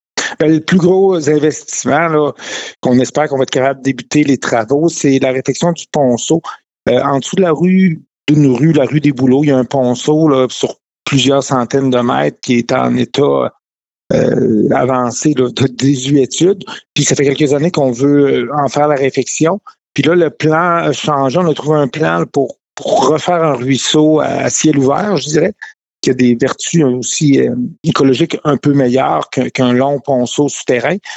Stéphane Gamache soutient que Murdochville devra renflouer son bassin de machinerie pour le déneigement et l’asphaltage et poursuivre avec la phase 2 du réaménagement des parcs. Le maire évoque aussi la réfection de ponceaux :